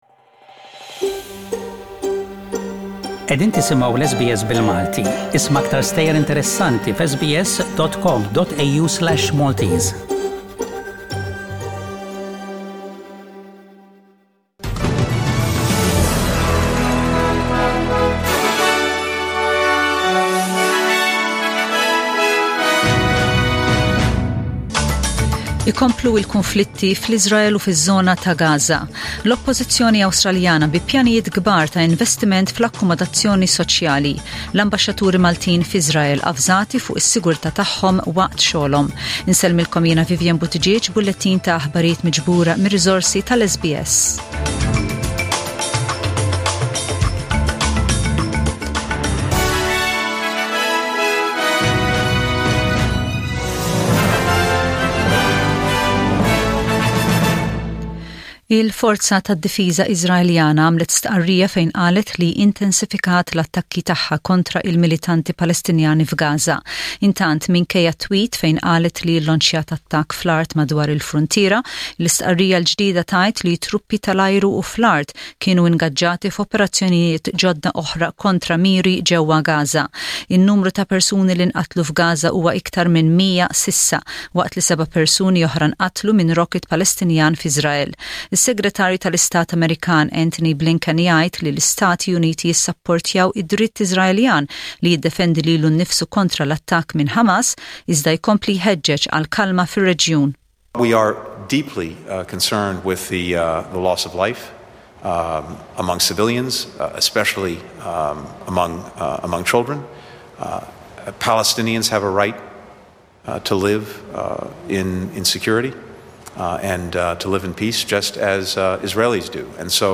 SBS Radio | Aħbarijiet bil-Malti: 14/05/21